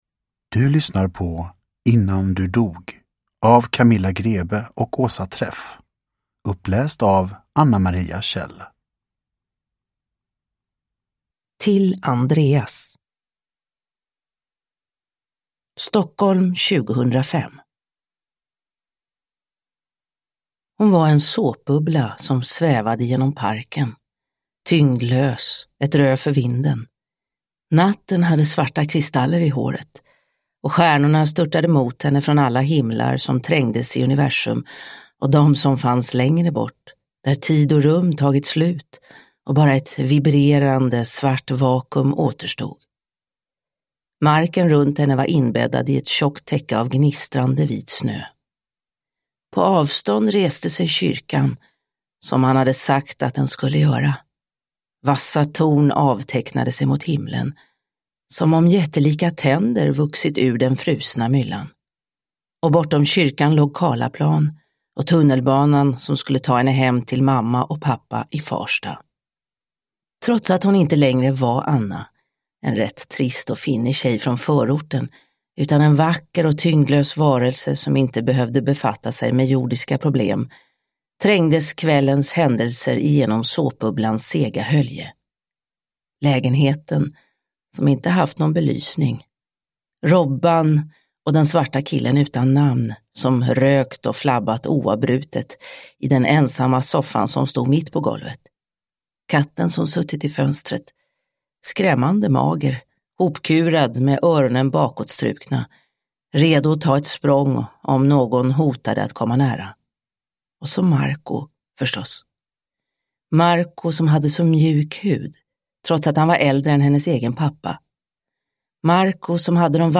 Innan du dog – Ljudbok – Laddas ner